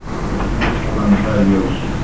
Escuche espectrales voces del mas alla. Estas son la llamadas Psicofonias. Voces de fanatsmas capturadas en radios , televisores sin señal y corrientes de agua.
Un hombre al parecer al estar muerto y perdido exclama: Me falta dios